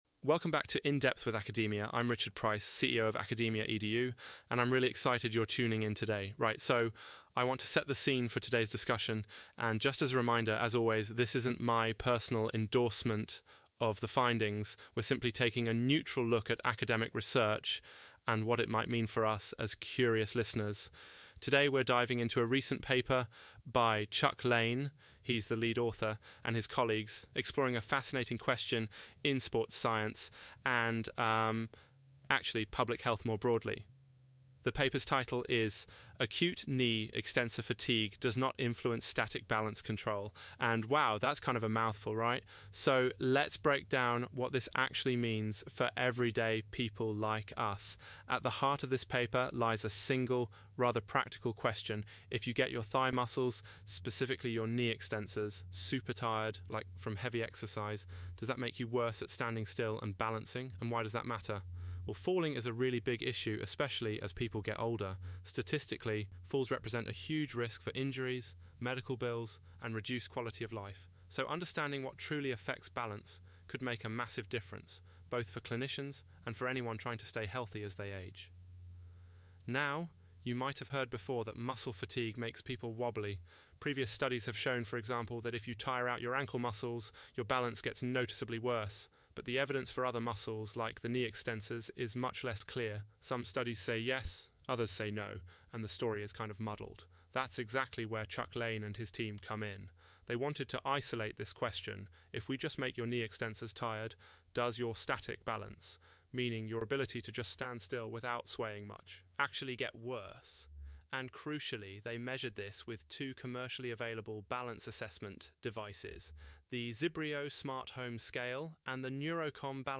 Automated Audio Summary